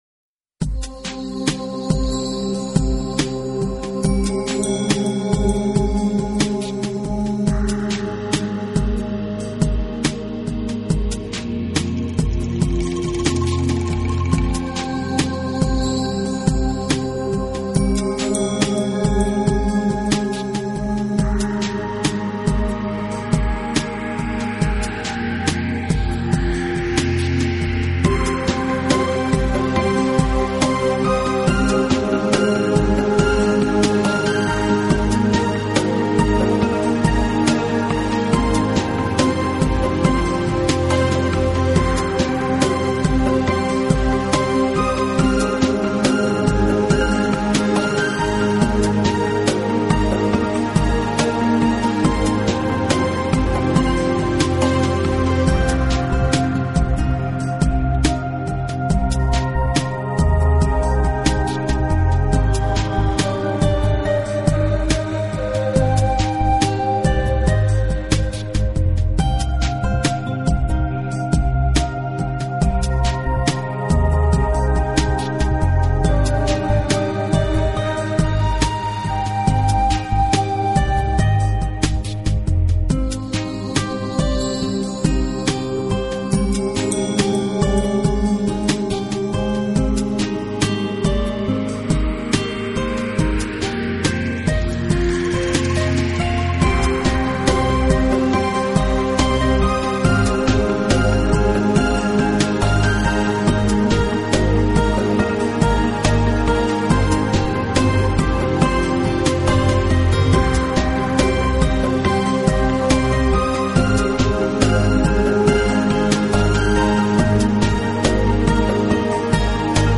音乐类型：New Age, Ambient, Enigmatic